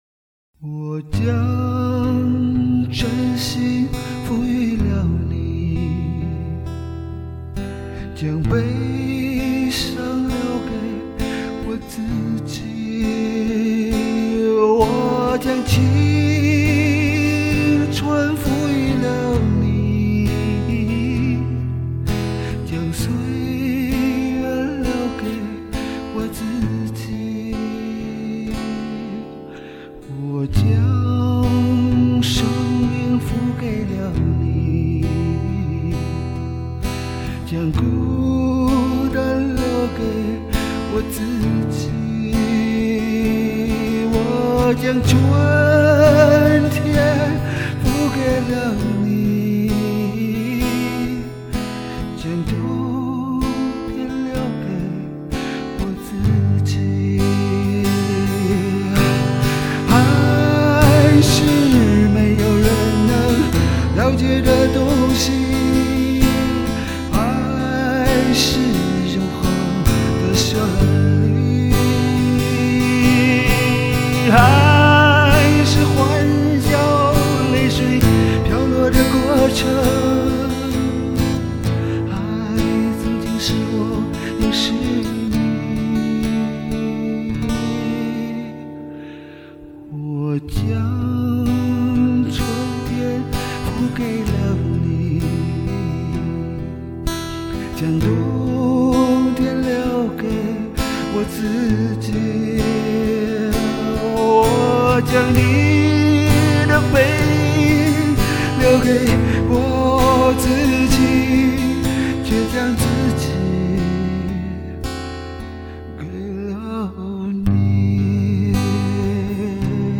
像是在悄声诉说内心深处的情感，深受感动！
老乡的这如泣如诉歌声感人至深。